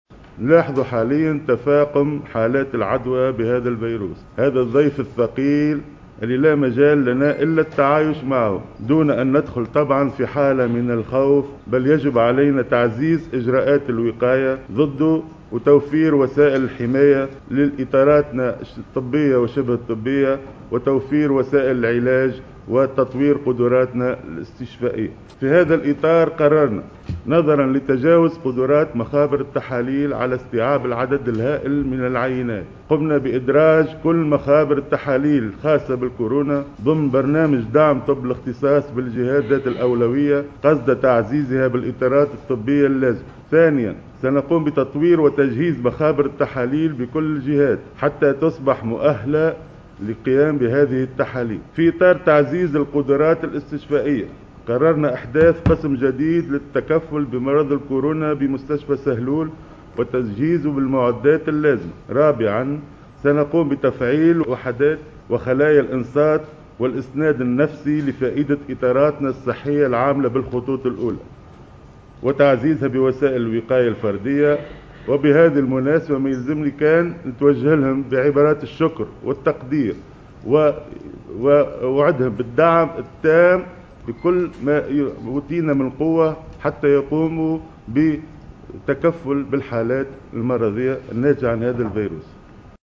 أعلن وزير الصحة فوزي المهدي، خلال ندوة صحفية عقدها بمقرّ الوزارة عن جملة من القرارات الخاصة بالتوقي من فيروس كورونا.